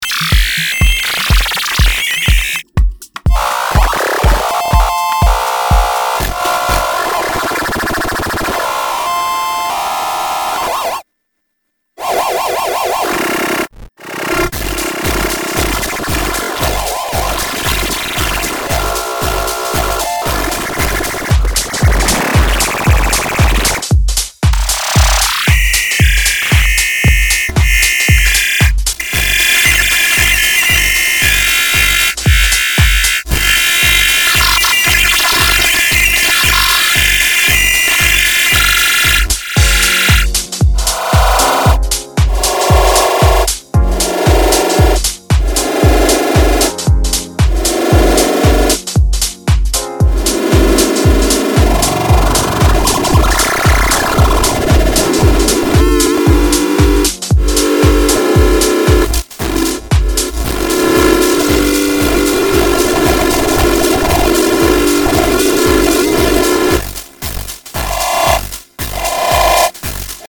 a FM synthesizer SGTL5000 & Teensy 4.1 – SUPPORTS MIDI VIA USB
Here's a demo of the latest version, the USB audio from the computer is slightly being messed with. Playing over house music and a key on the piano flips it into mutation mode or computer background music in iTunes.